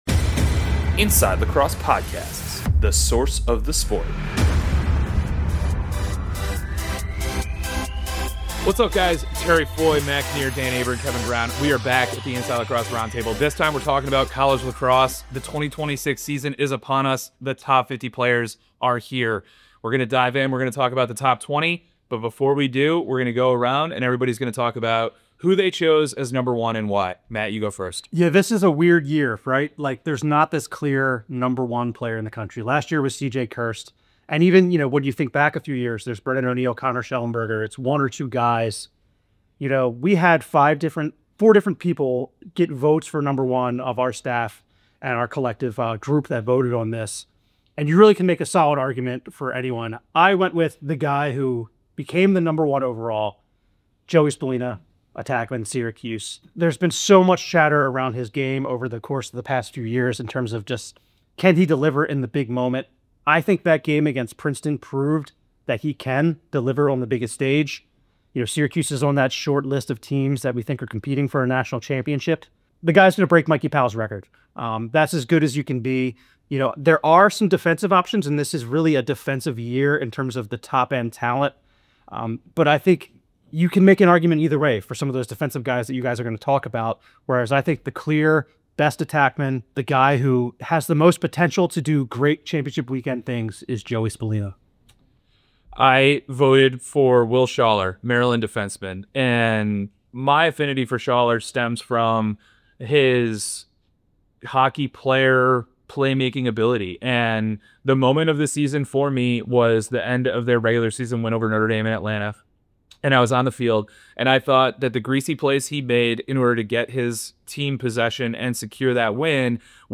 sit down around the roundtable to discuss the top 50 players in college lacrosse.